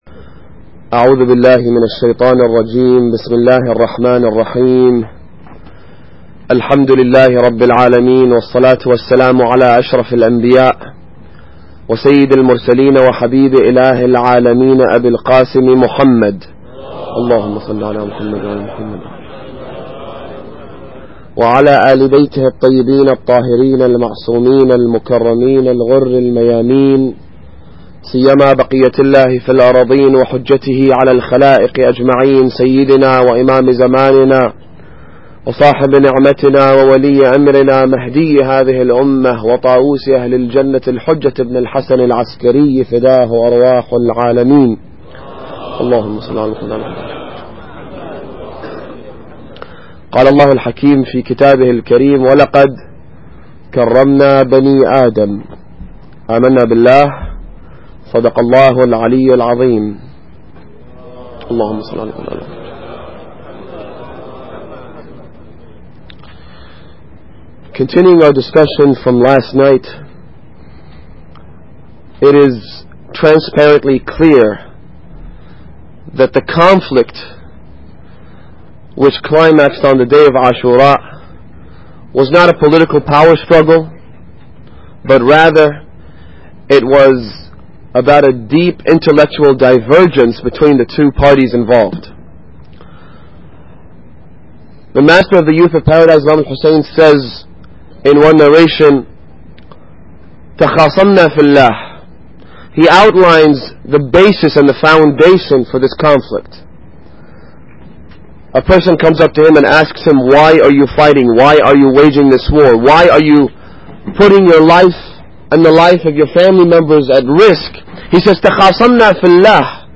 Muharram Lecture 2